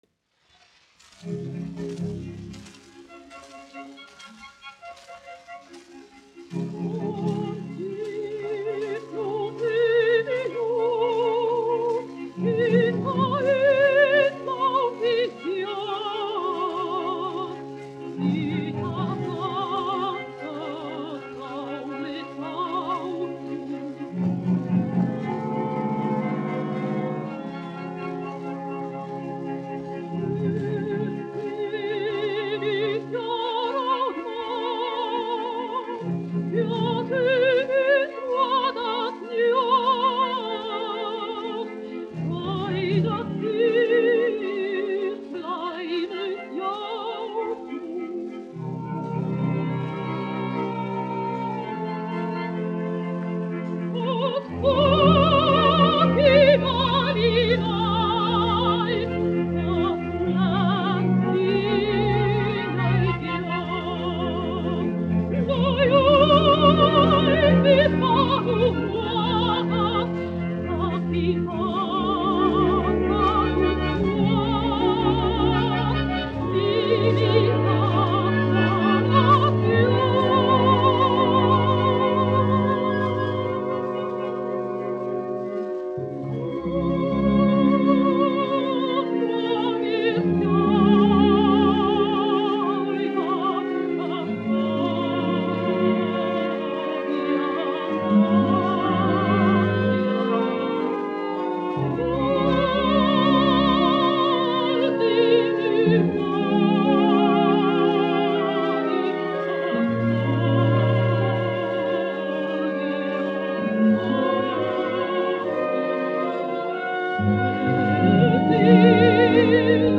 1 skpl. : analogs, 78 apgr/min, mono ; 25 cm
Operas--Fragmenti
Skaņuplate
Latvijas vēsturiskie šellaka skaņuplašu ieraksti (Kolekcija)